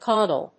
/ˈkɔdʌl(米国英語), ˈkɔ:dʌl(英国英語)/